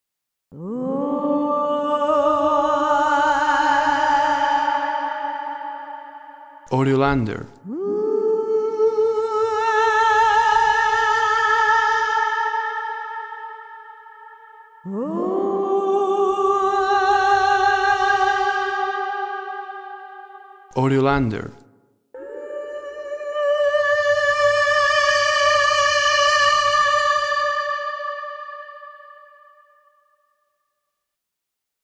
Female epic voice Only.
Tempo (BPM) 100